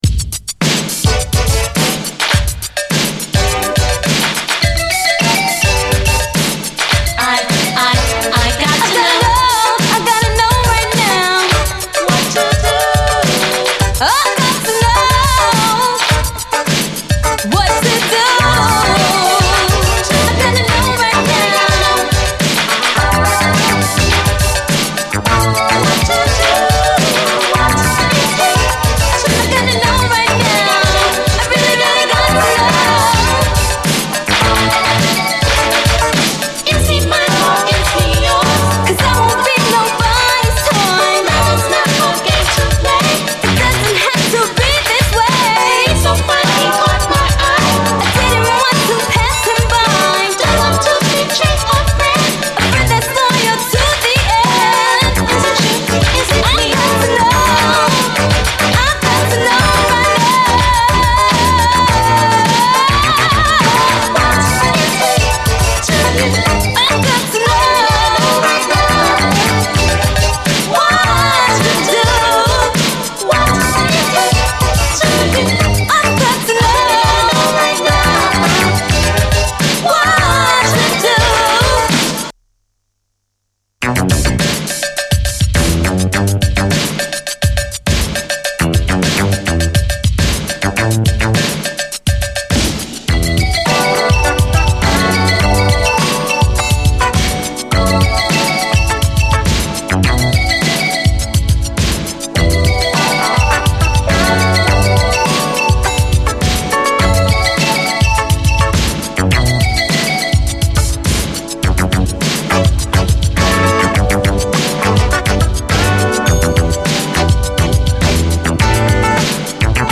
無敵のダンス・クラシック